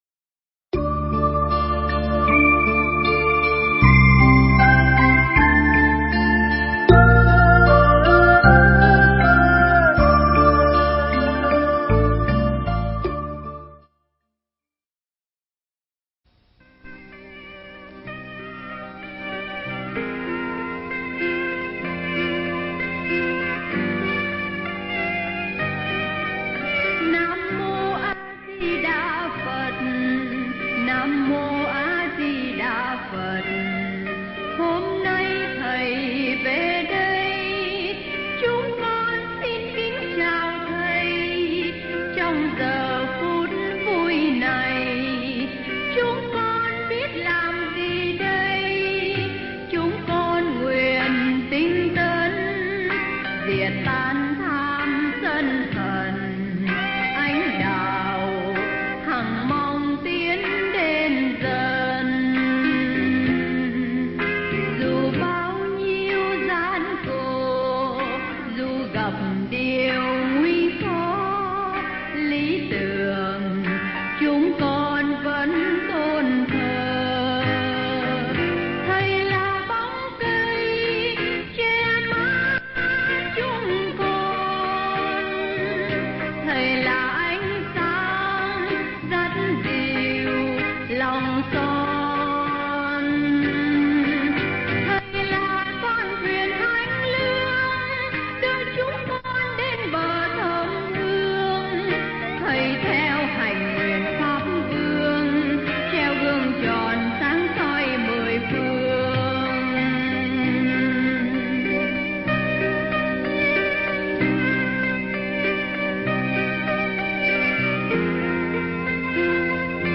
Tải mp3 pháp âm Vô Sư Trí – Hòa Thượng Thích Thanh Từ